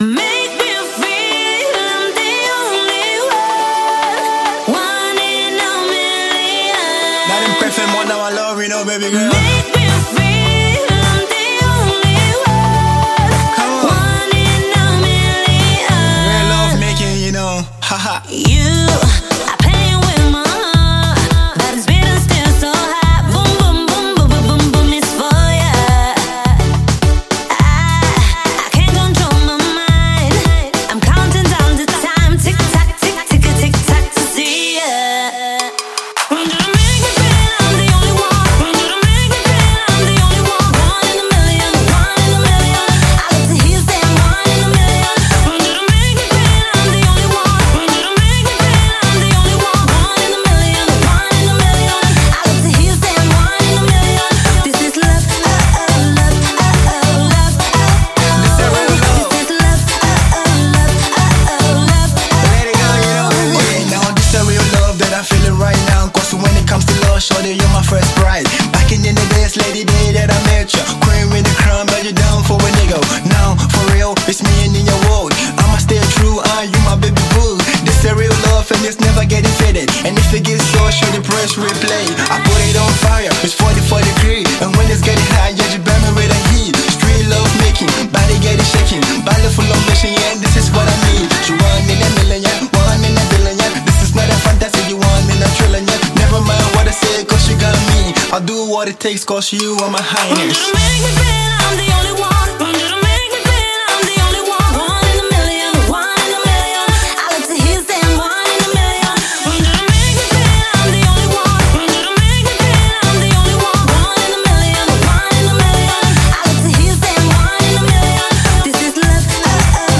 Žánr: Electro/Dance
smash dance hit